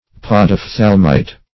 Podophthalmite \Pod`oph*thal"mite\, n.